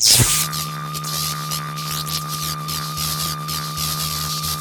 beamretract.ogg